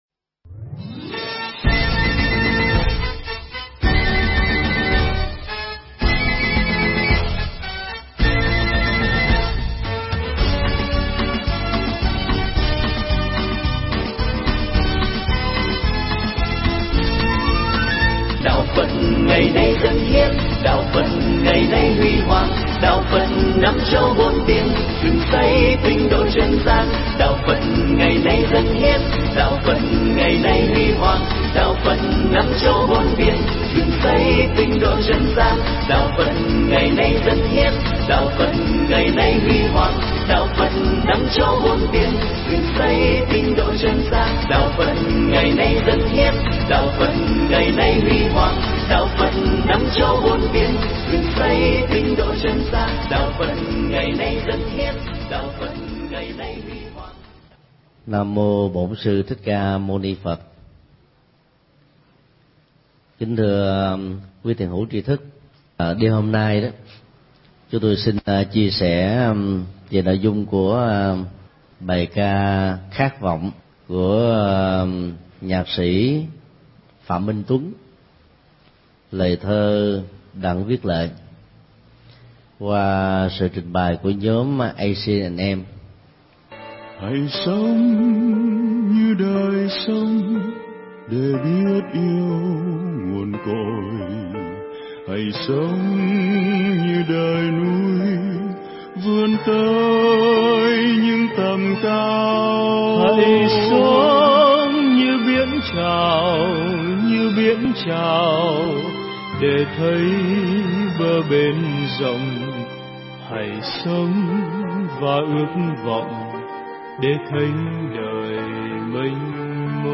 thuyết pháp Khát Vọng
giảng tại chùa Giác Ngộ